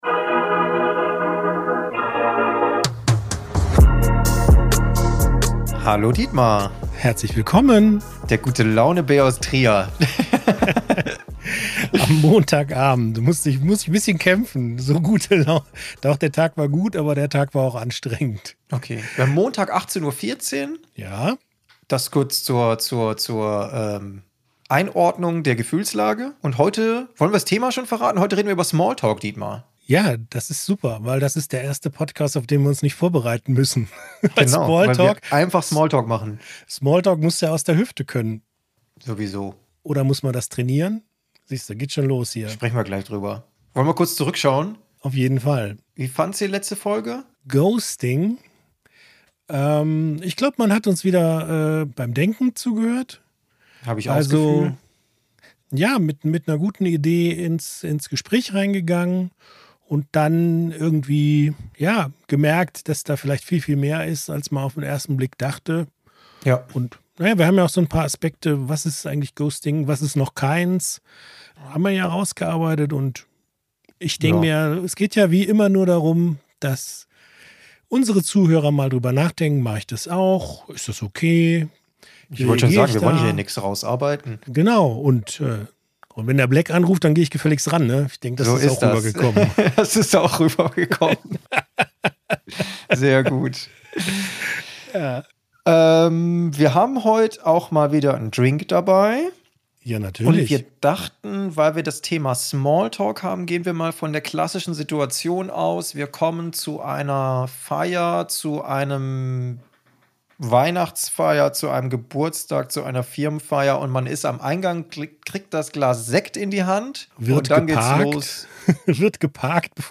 Ein BusinessTalk unter Freunden! Statt Lifecoaching und Karrieretipps gibt es Denkanstöße zu aktuellen Themen und wenn´s dumm läuft auch mal unqualifizierte Kommentare von qualifizierten Leuten.